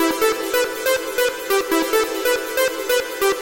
Tag: 140 bpm House Loops Synth Loops 590.80 KB wav Key : Unknown